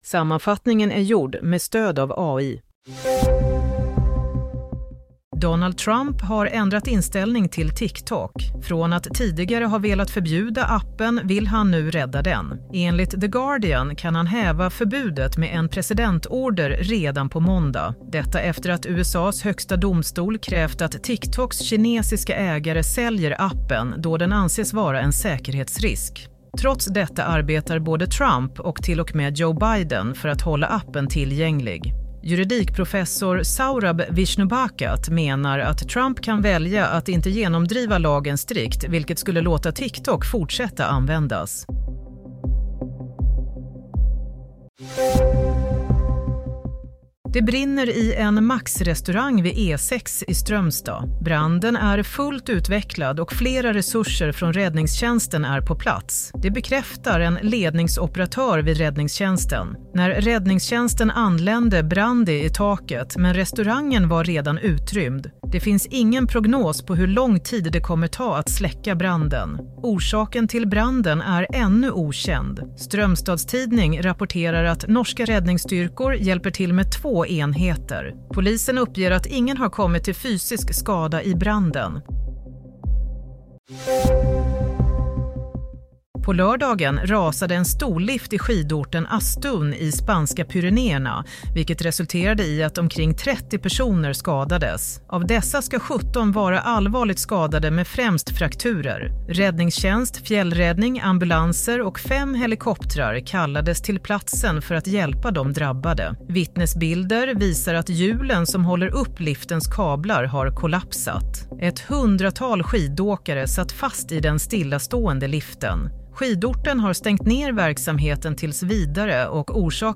Nyhetssammanfattning - 18 januari 16:00